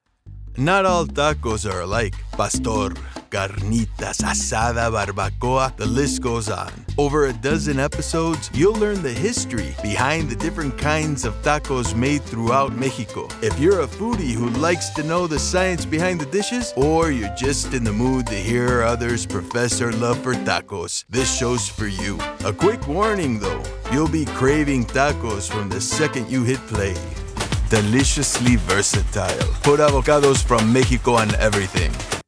Bilingual